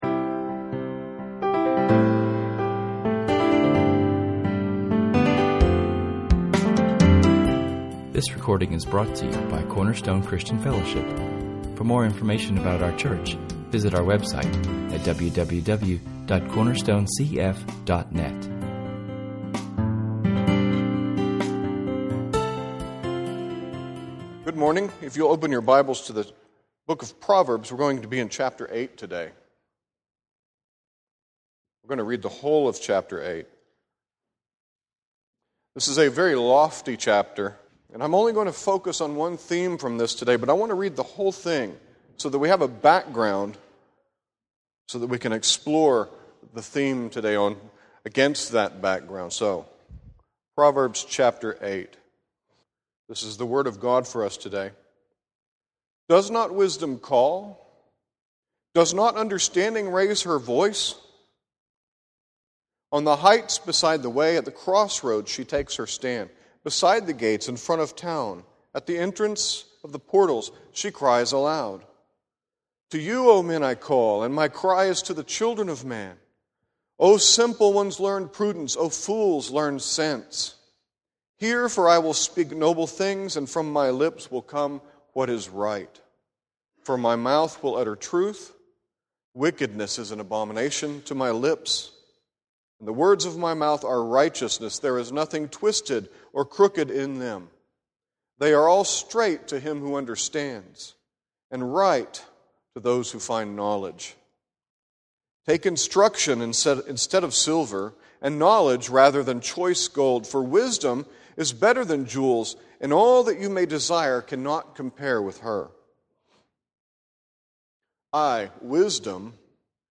What He asks of us, He equips us to do. In this sermon we look at the urgency of Wisdom’s call, the public nature of Wisdom’s call, the personal aspect of the call to be wise, and finally the title point, that Wisdom is near by.